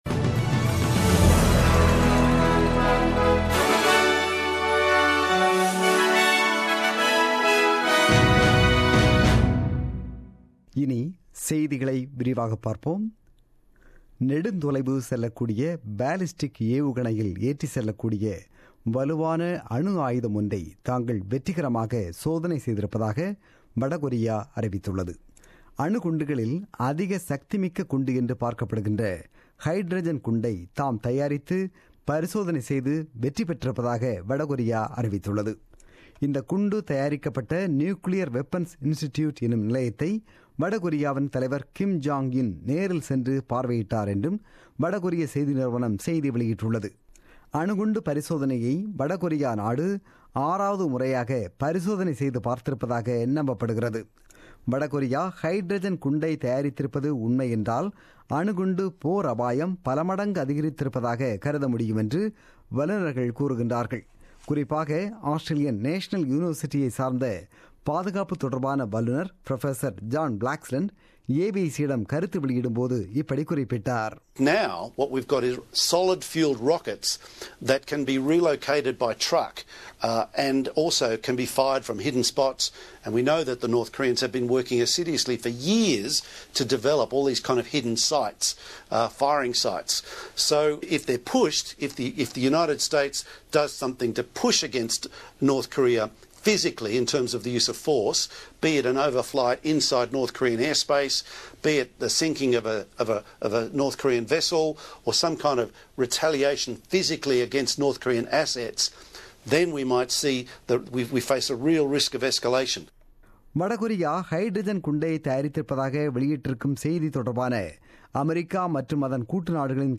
The news bulletin broadcasted on 3 September 2017 at 8pm.